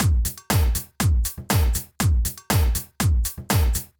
Drumloop 120bpm 06-A.wav